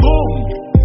Play, download and share Boom e6y original sound button!!!!
boom-e6y.mp3